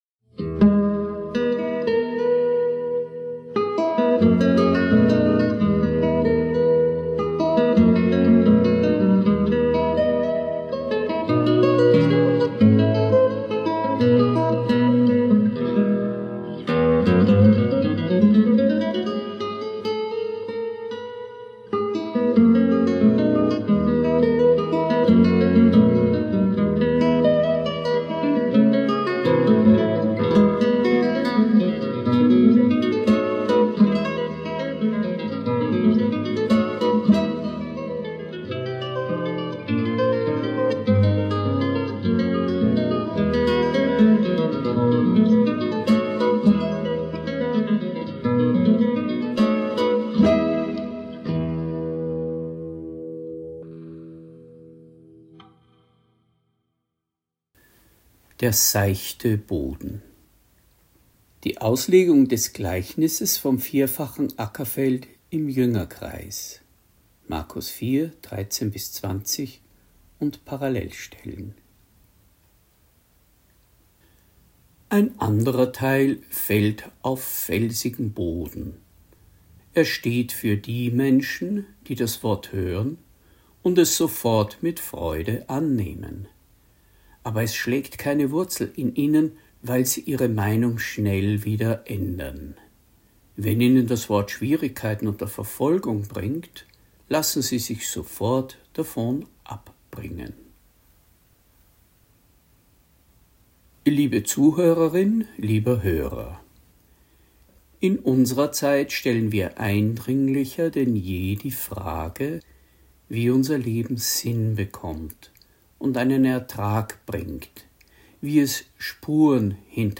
Predigt | NT02 Markus 4,13-20 Das 4-fache Ackerfeld (3) Seichter Boden – Glauben und Leben